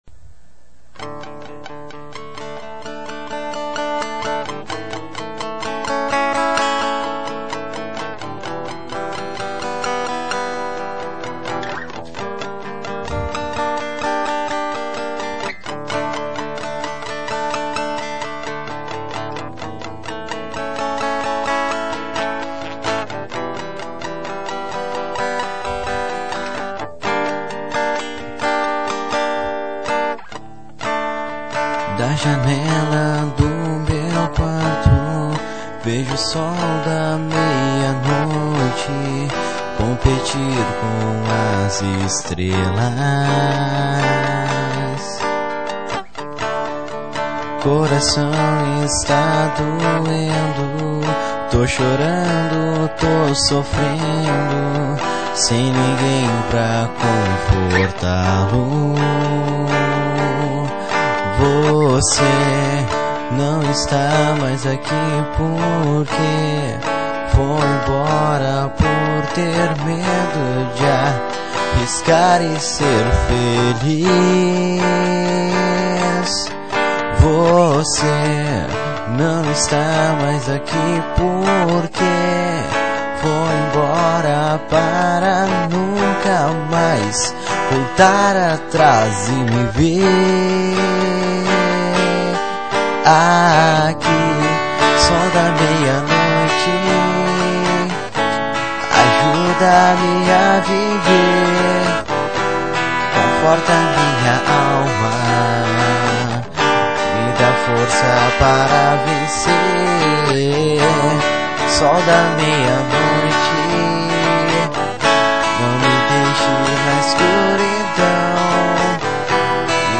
EstiloPop